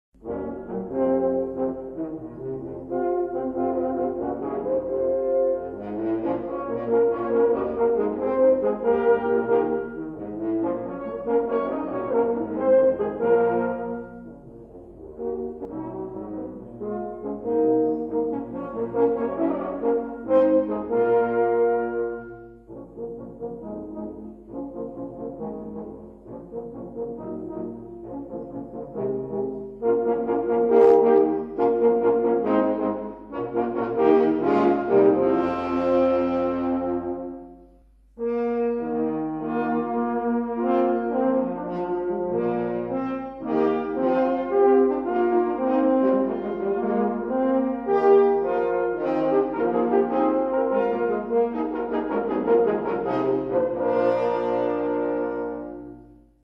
Für Hornquartett